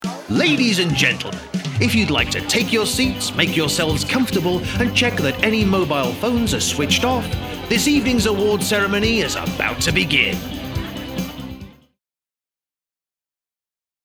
Versatile, Eloquent & Engaging
Voice of God
Voice Overs